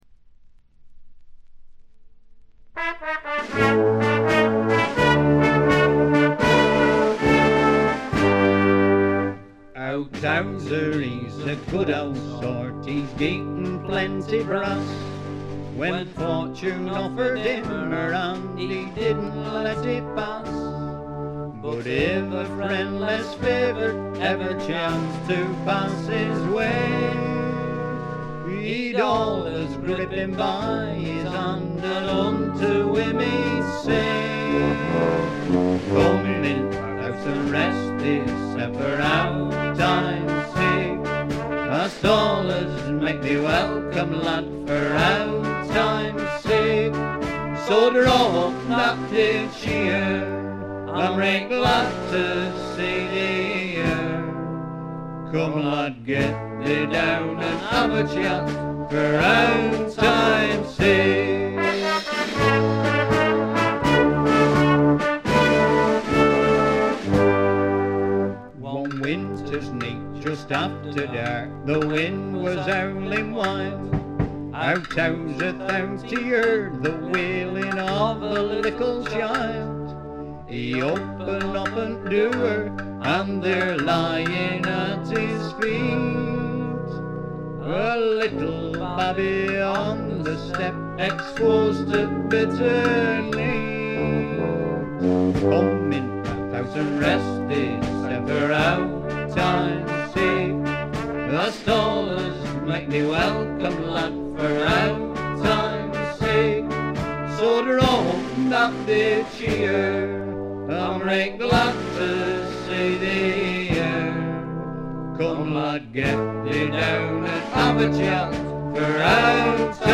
部分試聴ですがほとんどノイズ感無し。
試聴曲は現品からの取り込み音源です。
banjo
guitar
vocals, chorus, mandolin, whistle